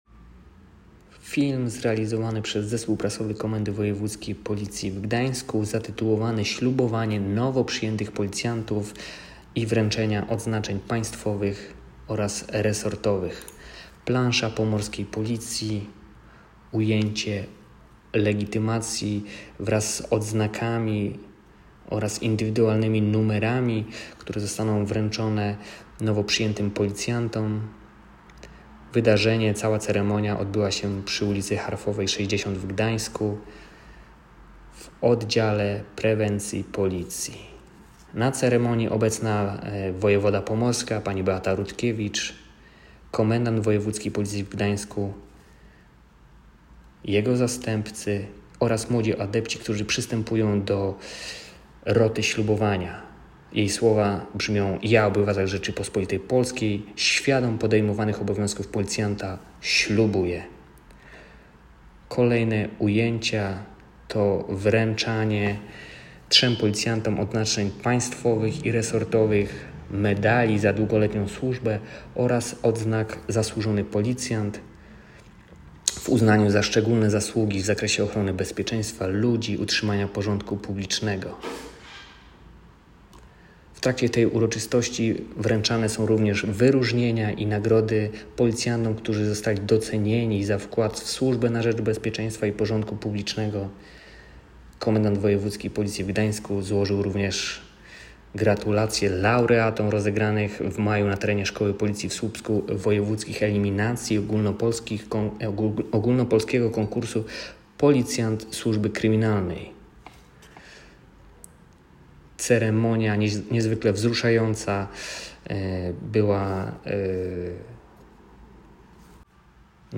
Nagranie audio Audiodyskrypcja